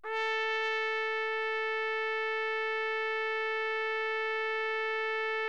TRUMPET   16.wav